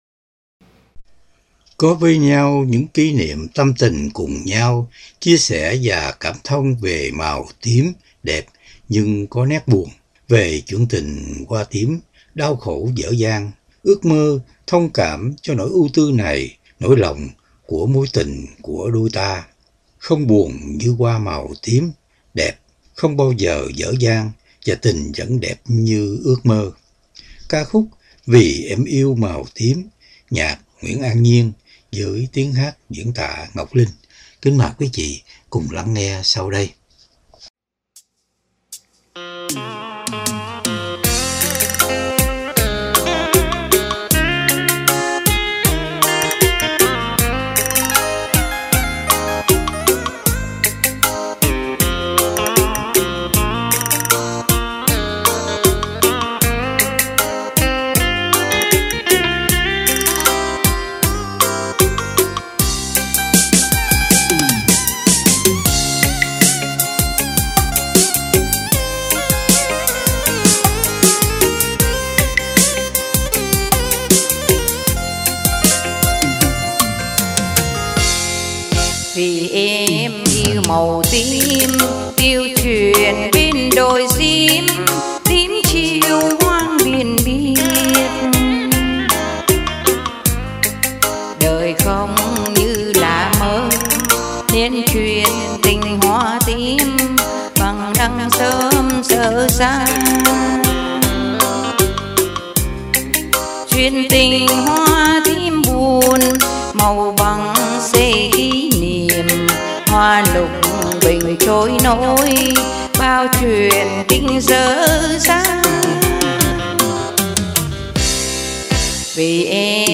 Âm Nhạc